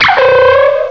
cry_not_sawk.aif